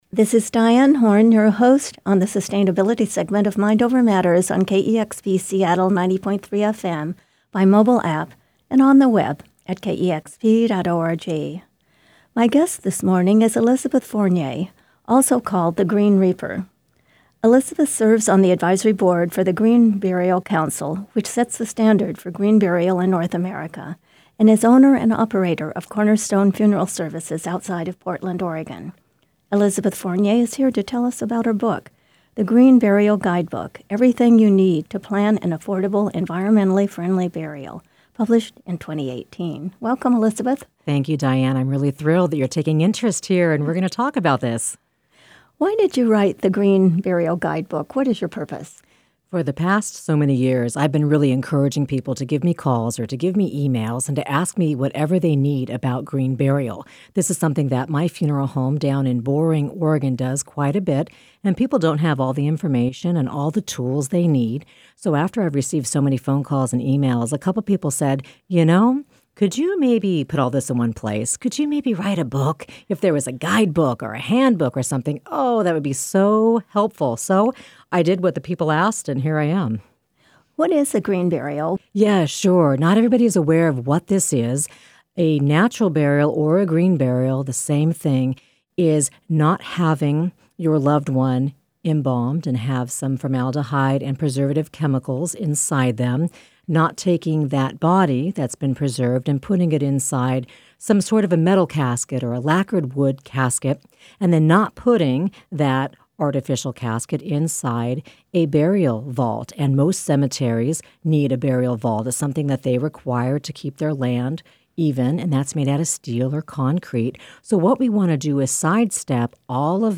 KEXP - Mind Over Matters Sustainability Segment Interview, 90.3FM, Seattle, WA